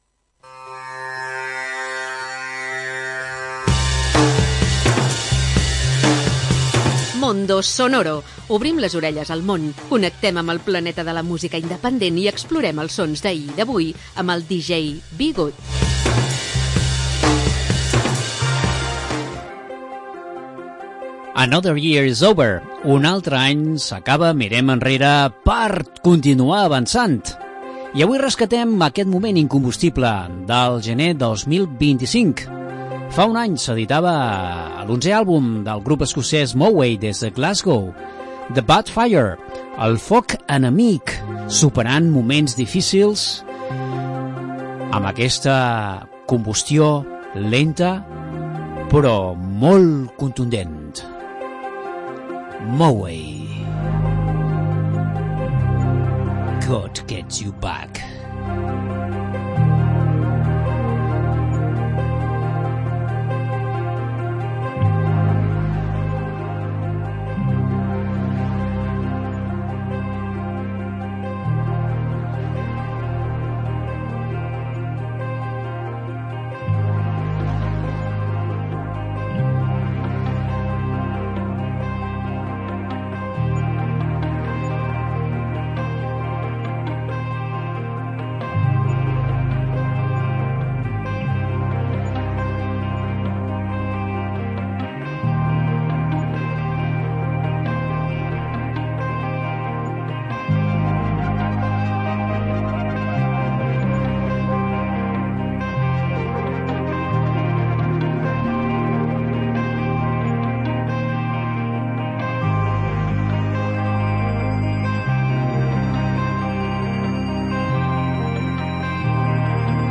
Una selecció de música amb esperit independent